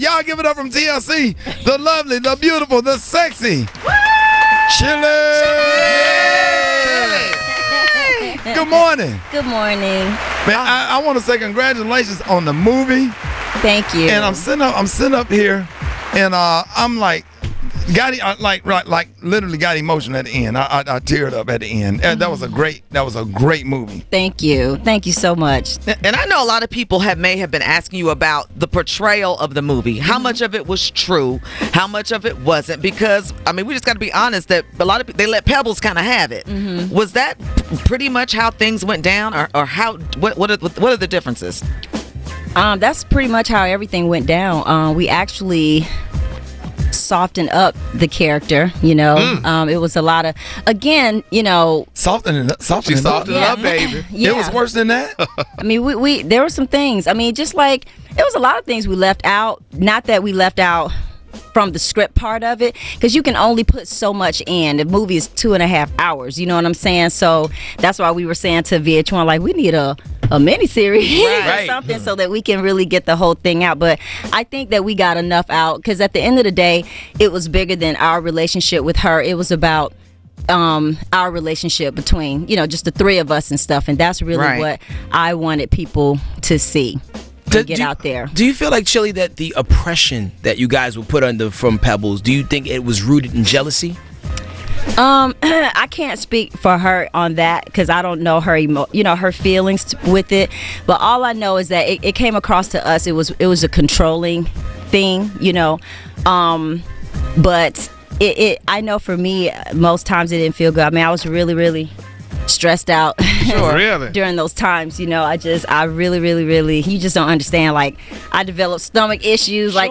Chilli Interview Part 1: